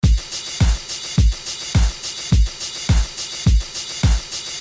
舞蹈鼓循环1
Tag: 105 bpm Dance Loops Drum Loops 787.67 KB wav Key : Unknown